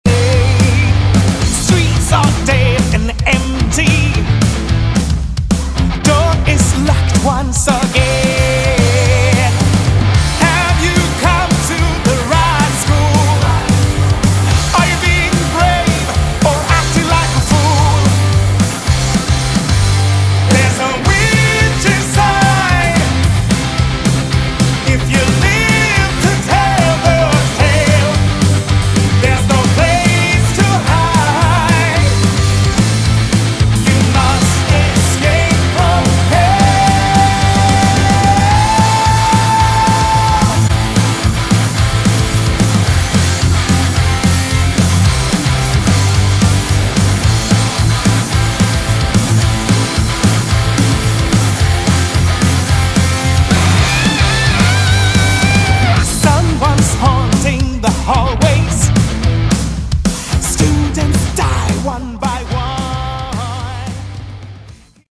Category: Hard Rock
lead vocals
bass
guitar
drums